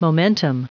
Prononciation du mot momentum en anglais (fichier audio)
Prononciation du mot : momentum